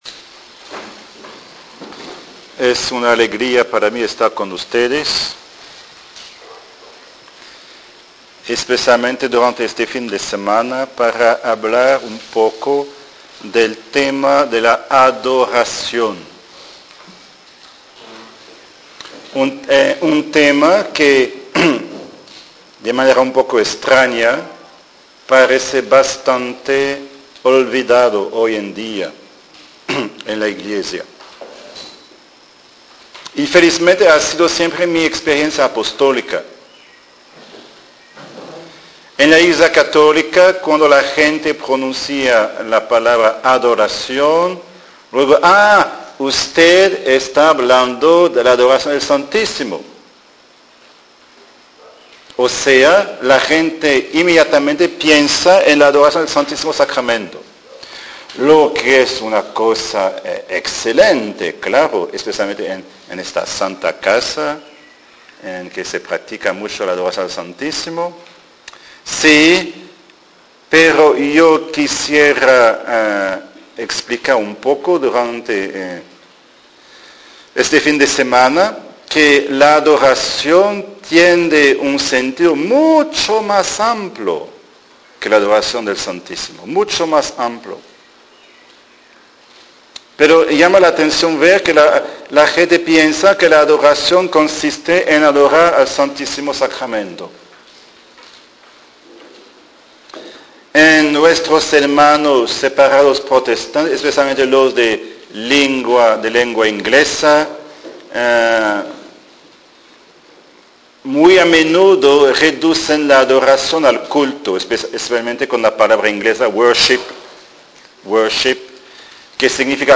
Cómo adorar a Dios. Charlas en audio mp3